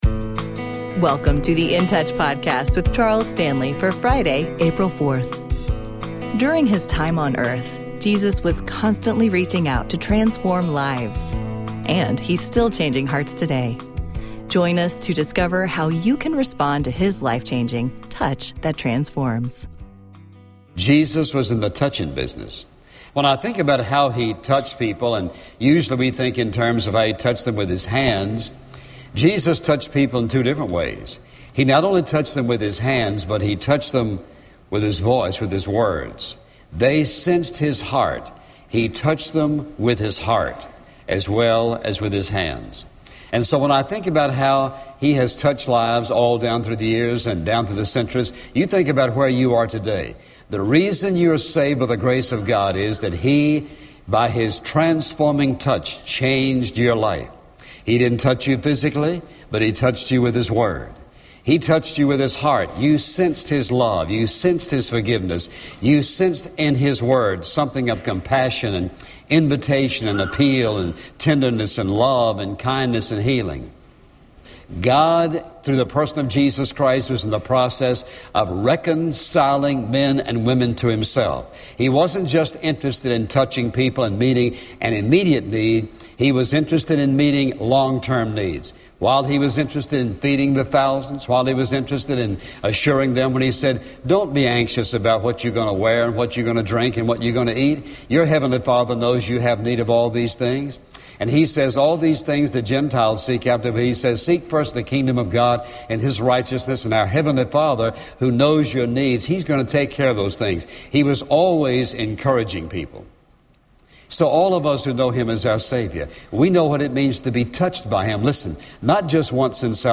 Dr. Charles Stanley and In Touch Ministries’ daily radio program.